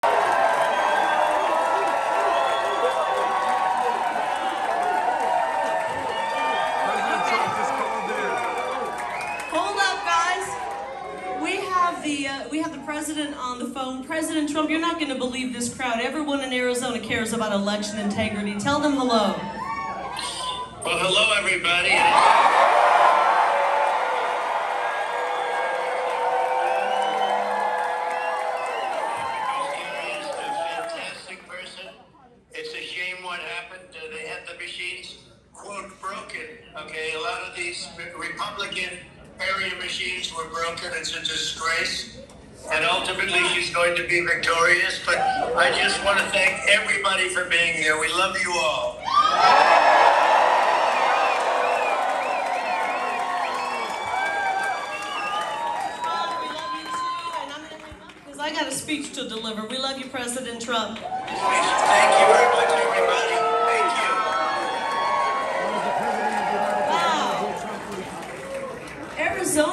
President Trump Calls Into Kari Lake’s Save Arizona Rally